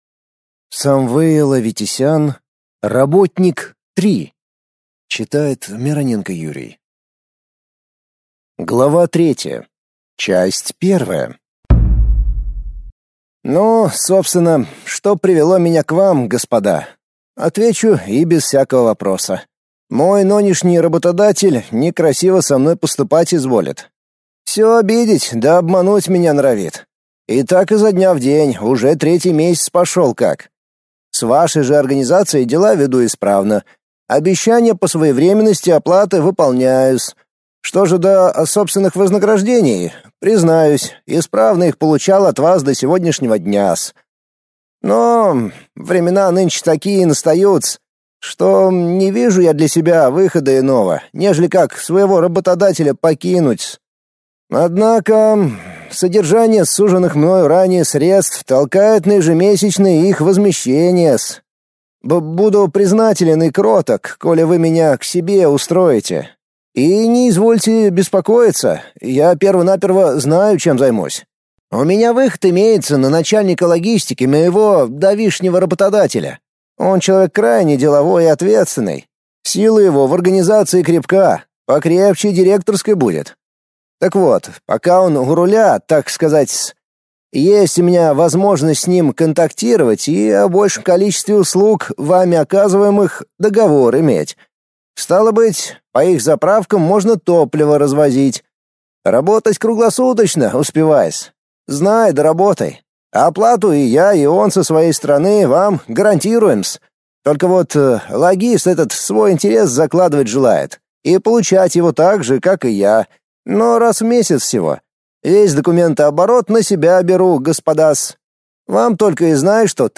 Aудиокнига Работник 3